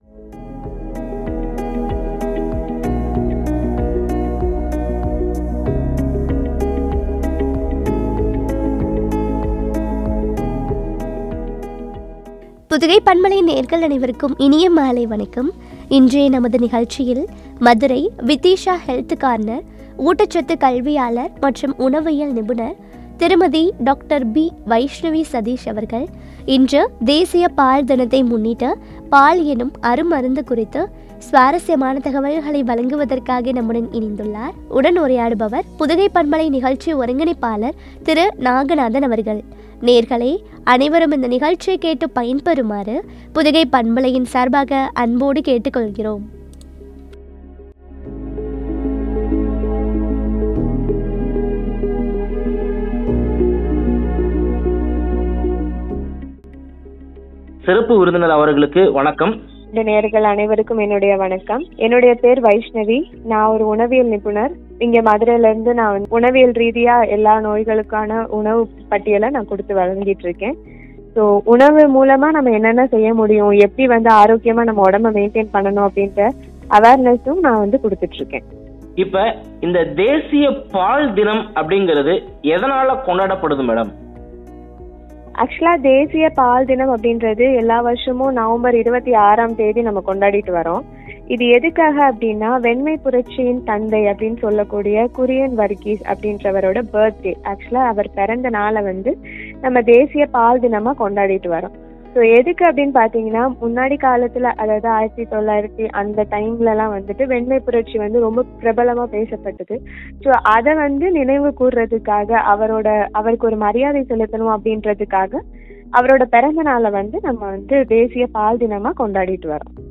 பால் எனும் அருமருந்து!! குறித்து வழங்கிய உரையாடல்.